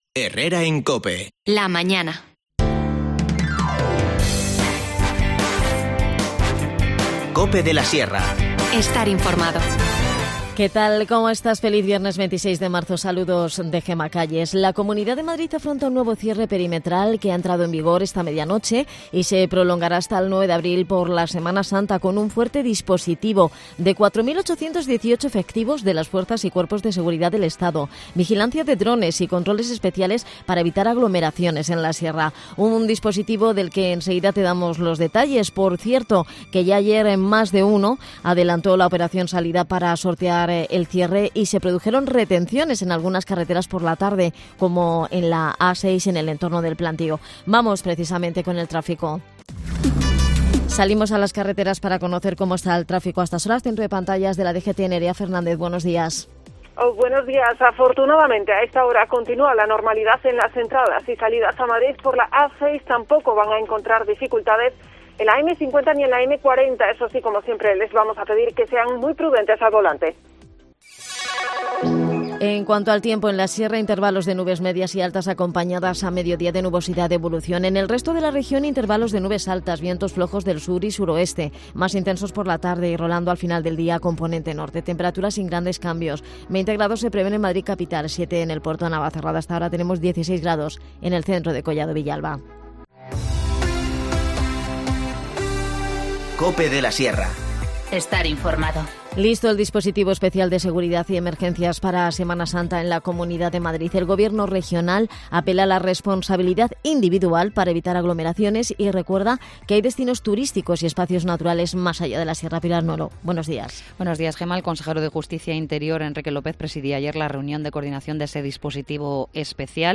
Tenemos buenas noticias para toda la gran familia de la Agrupación Deportiva de La Sierra: Depués de Semana Santa comienzan las competiciones. Nos lo cuenta Juan Carlos Rodriguez Osuna, alcalde de Moralzarzal y presidente de la ADS